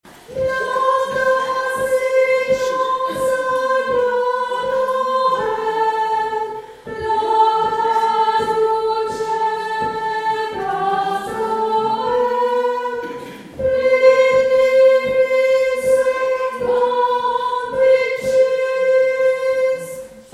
Lauda-Sion-S.mp3